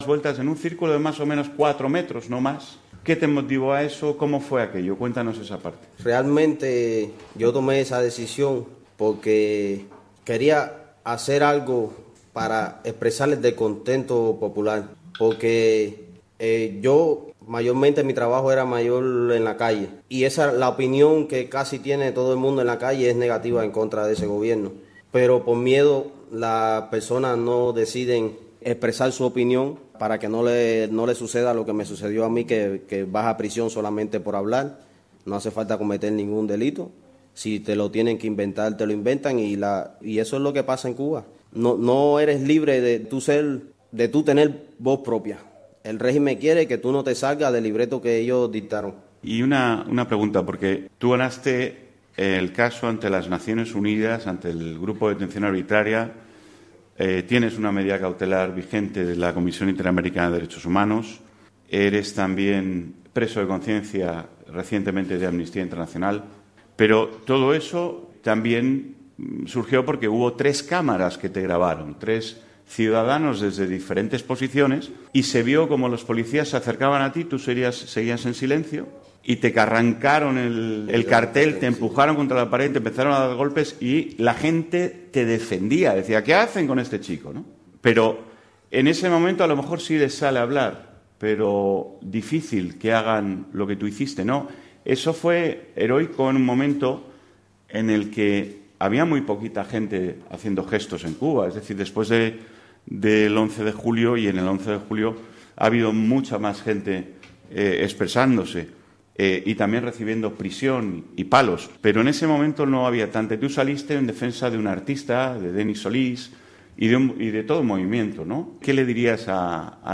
espacio informativo en vivo